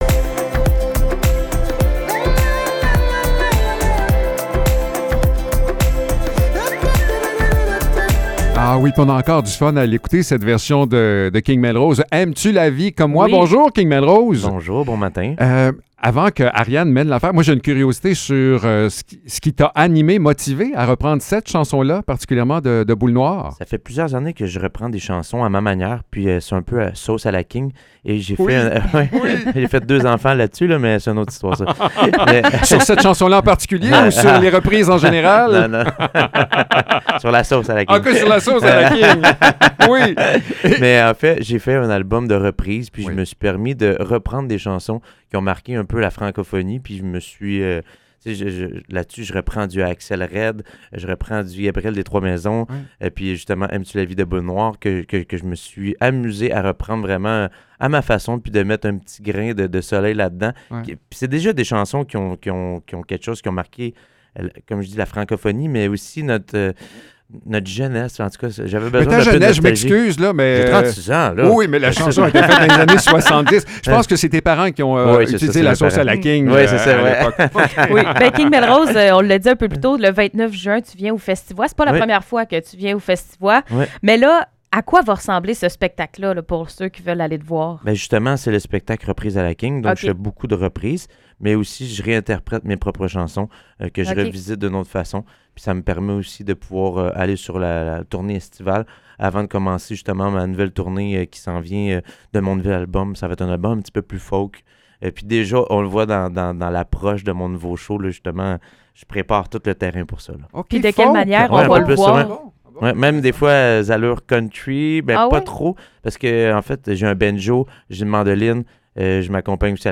Entrevue
performance musicale !